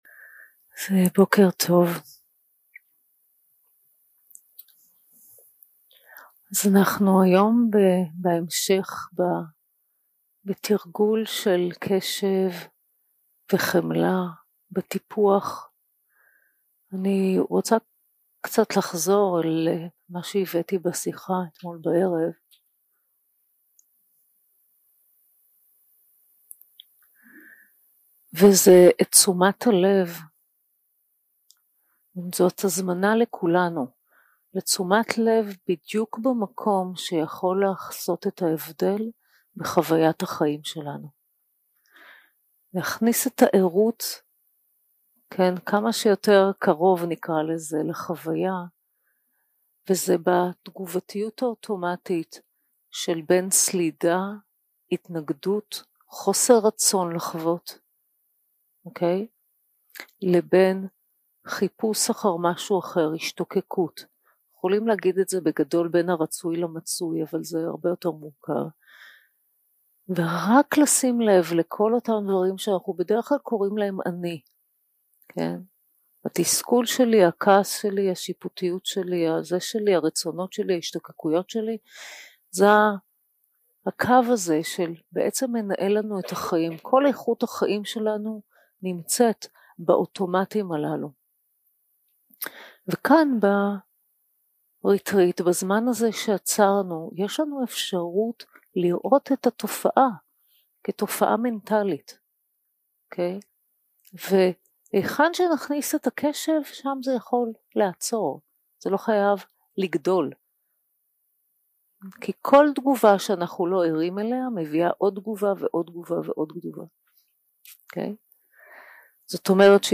יום 4 – הקלטה 10 – בוקר – מדיטציה מונחית – טיפוח קשב וחמלה 0:00 0:00 סוג ההקלטה: Dharma type: Guided meditation שפת ההקלטה: Dharma talk language: Hebrew